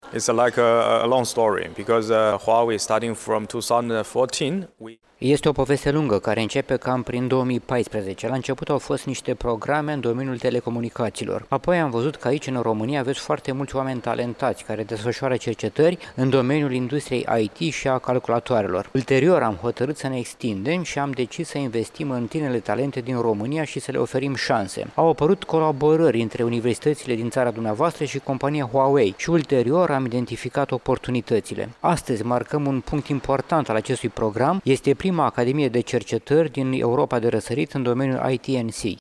La festivitatea de la Iaşi